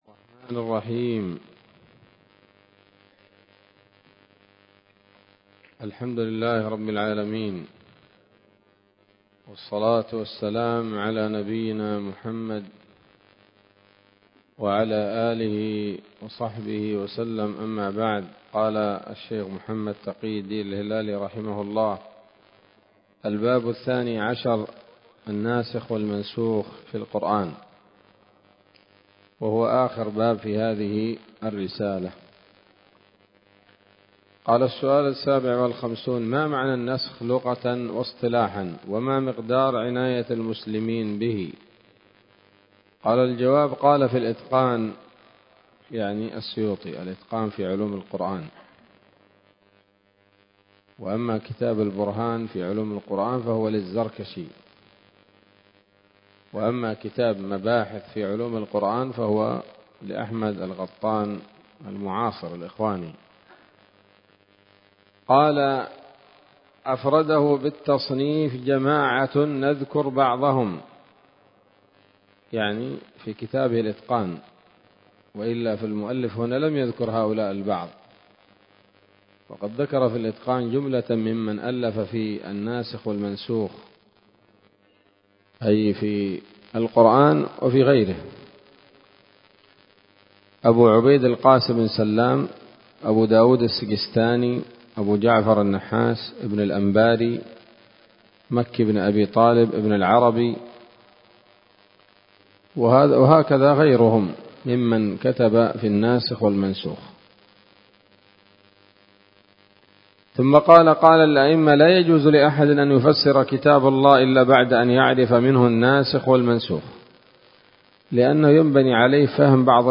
الدرس التاسع عشر من كتاب نبذة من علوم القرآن لـ محمد تقي الدين الهلالي رحمه الله